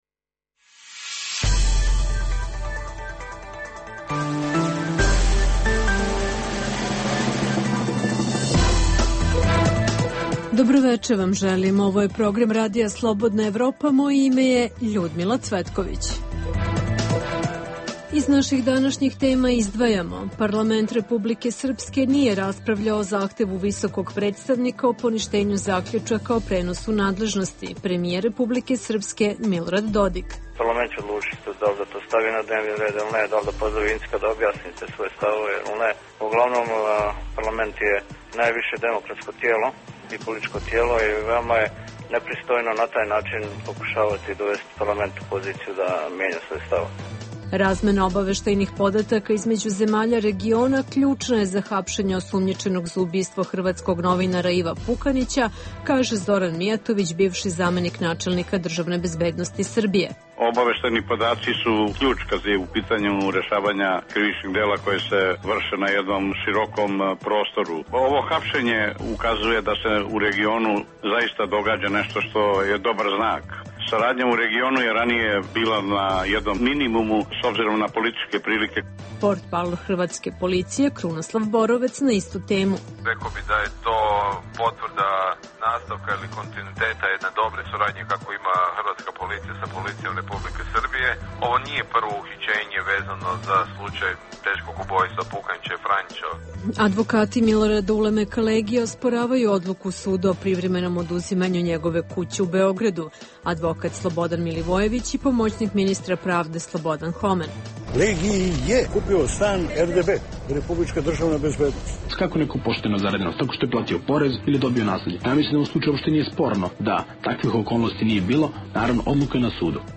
U regionalnom programu istražujemo kako bi Parlament Republike Srpske mogao da odgovori na zahtev Visokog predstavnika za poništavanje odluke o prenosu nadležnosti, mogu li policije u regionu biti efikasnije u borbi protiv kriminala, a povodom hapšenja u Beogradu osumnjičenog za ubistvo hrvatskog novinara Iva Pukanića, te ko je stvarni pobednik a ko gubitnik lokalnih izbora u Hrvatskoj. U Dokumentima dana: Specijalni sud za organizovani kriminal raspravlja o oduzimanju imovine Miloradu Ulemeku Legiji, optuženom za niz teških krivičnih dela, pre svega ubistvo premijera Zorana Đinđića, o ustavnim promenama u BiH i opomeni medjunarodne zajednice, da li je trgovinski rat izmedju BiH i Hrvatske završen, a čućete i svedoke u slučaju Morinj pred crnogorskim sudom.